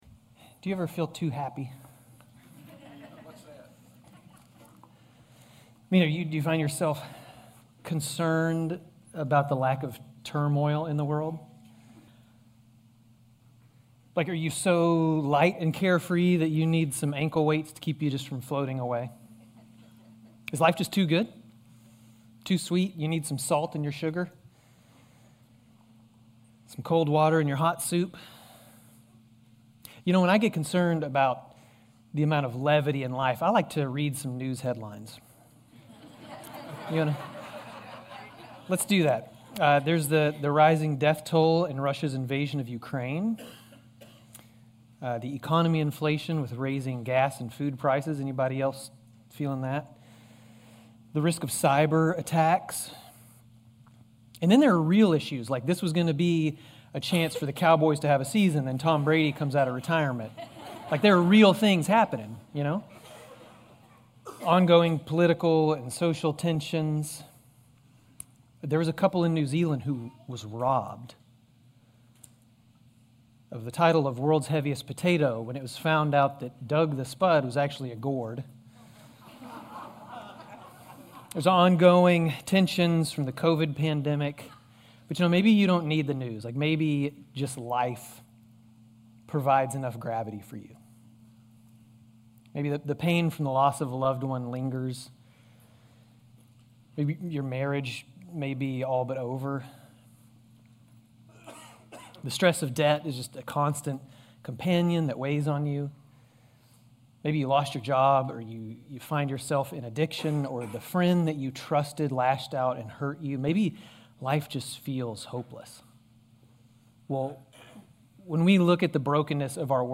GCC-Lindale-March-20-Sermon.mp3